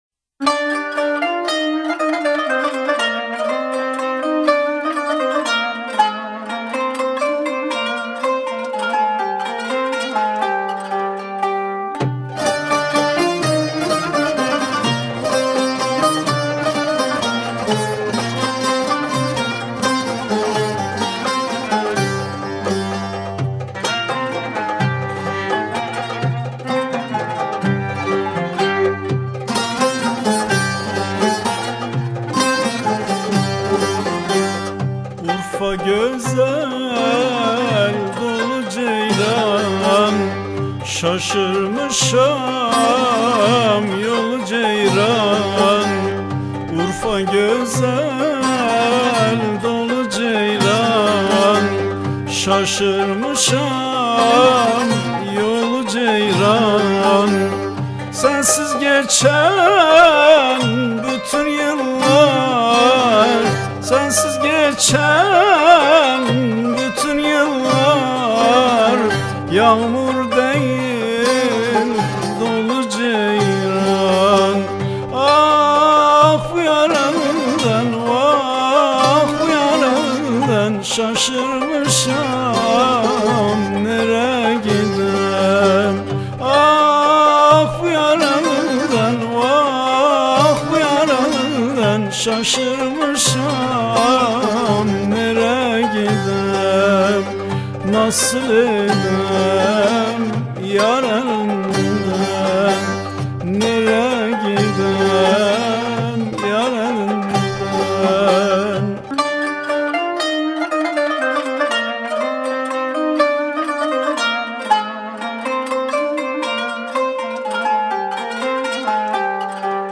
Etiketler: urfa, türkü